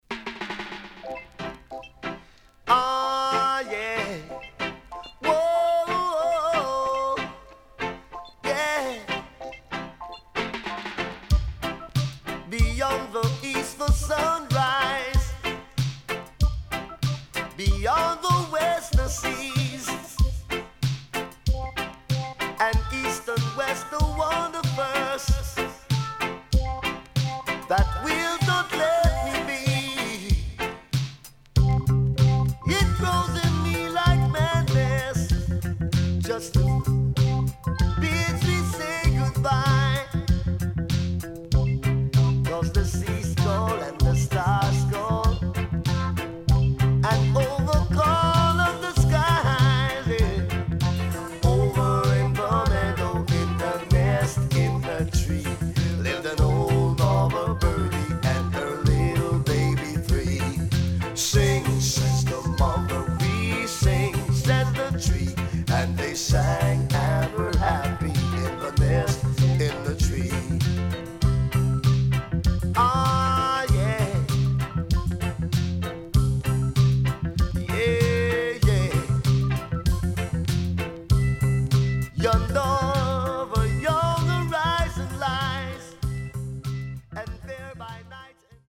HOME > LP [DANCEHALL]  >  EARLY 80’s
SIDE A:少しノイズ入りますが良好です。